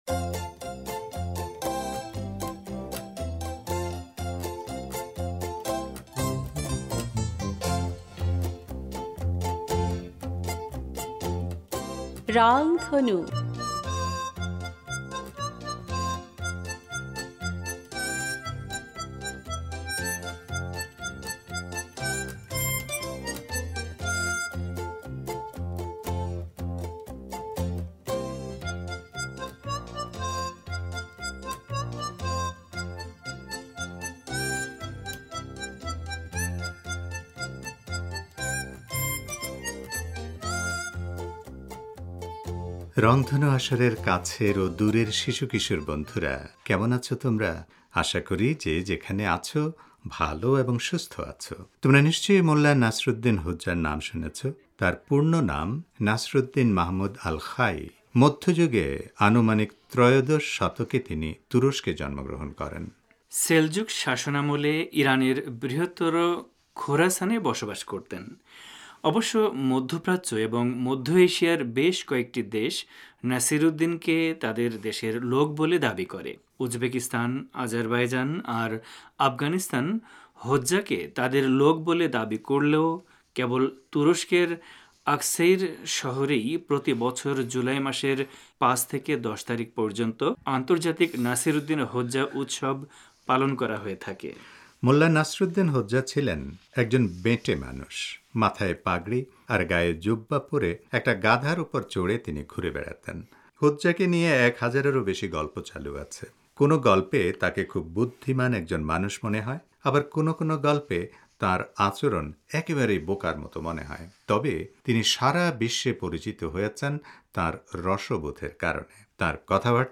যাই হোক, রংধনু আসরের এ পর্বে বিখ্যাত সেই মানুষটির কয়েকটি মজার ঘটনা তোমাদের শোনাব। আর সবশেষে থাকবে একটি দেশের গান।